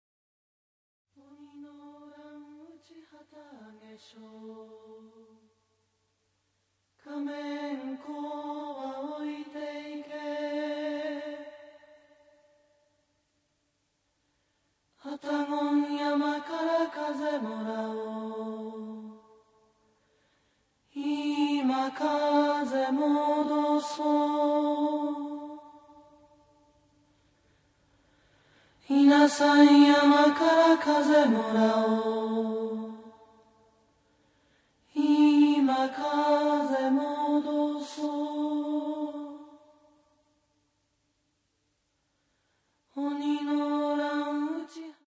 艶めいた声でせつせつと歌い上げる